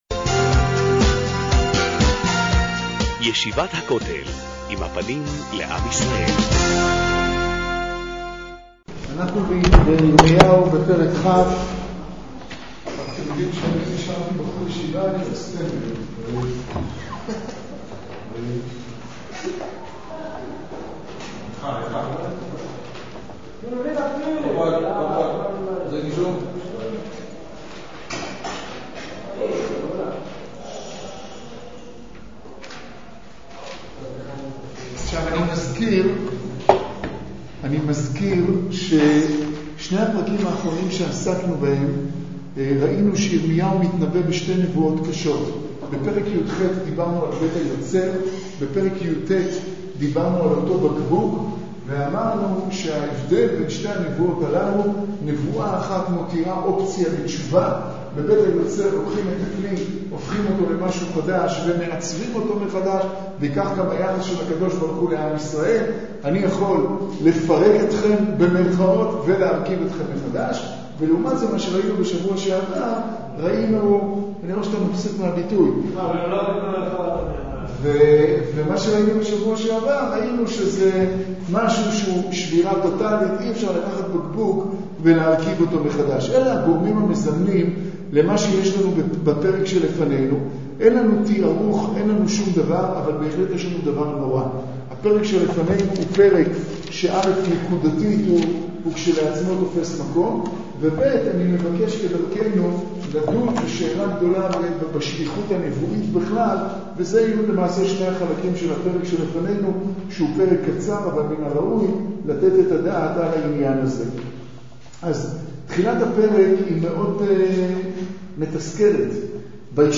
ירמיהו שיעור כ"ג פרק כ'